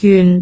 speech
syllable
pronunciation
gyun4.wav